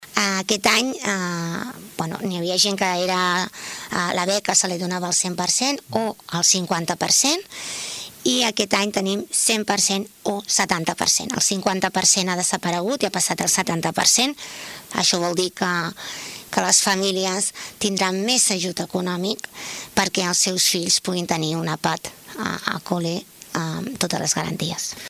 Escoltem la regidora de serveis socials, Toñi Garcia.